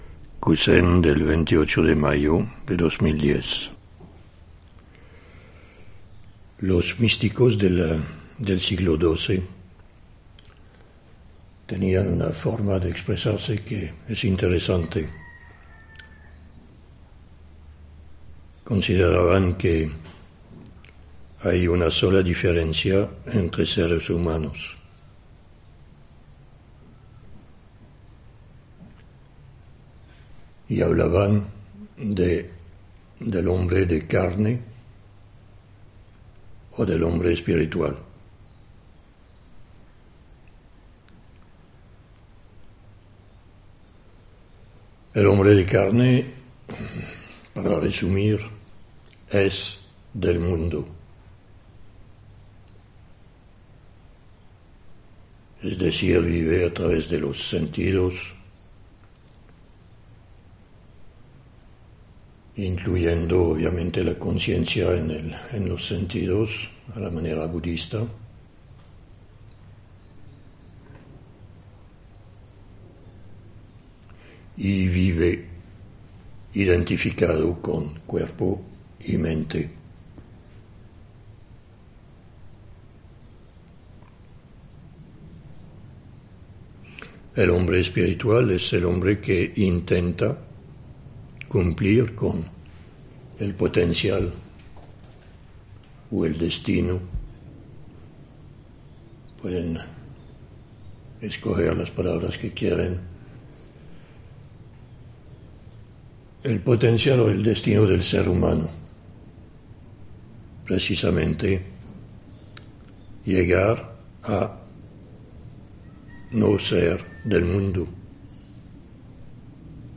El kusén es la enseñanza oral dada durante zazén.